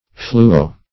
Search Result for " fluo-" : The Collaborative International Dictionary of English v.0.48: Fluo- \Flu"o-\ (Chem.) A combining form indicating fluorine as an ingredient; as in fluosilicate, fluobenzene.